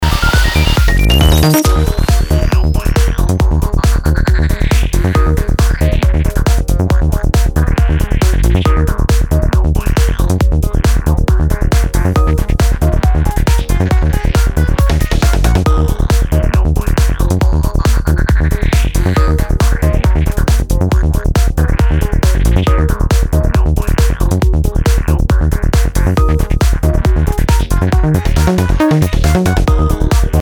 Квакающий звук